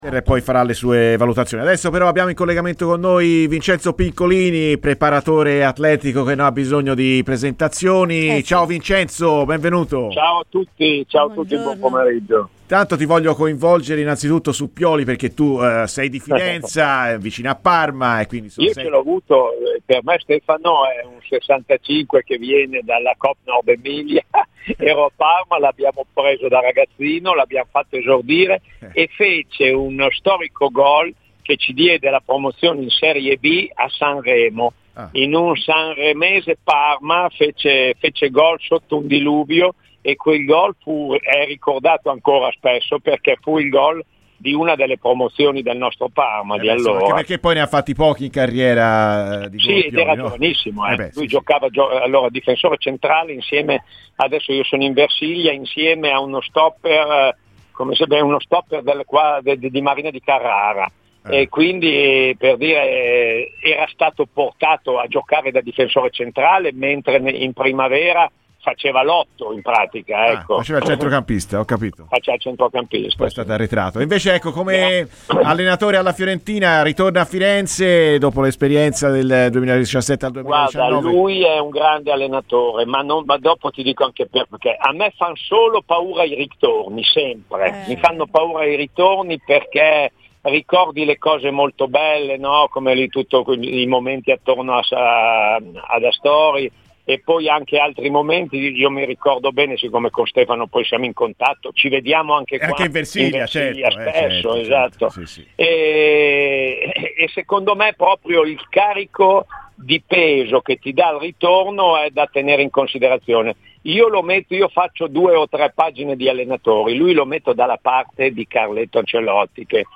Cosa pensa del suo ritorno a Firenze?